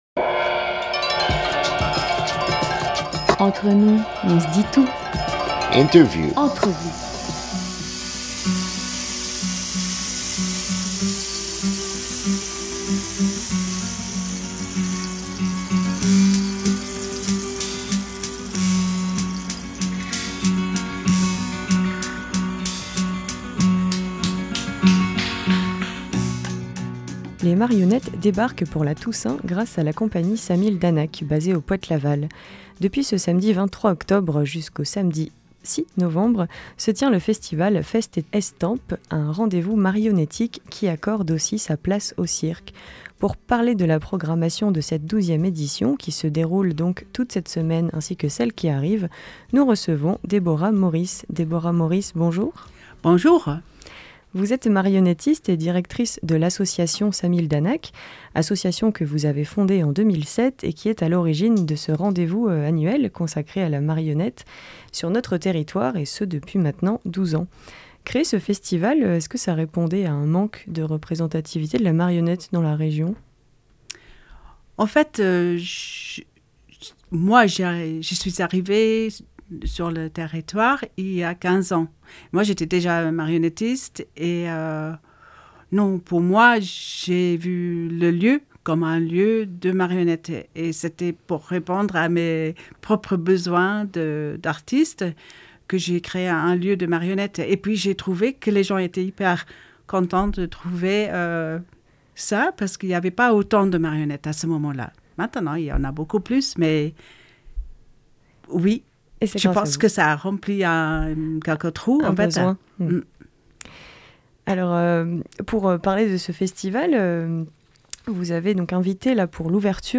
21 octobre 2021 14:20 | Interview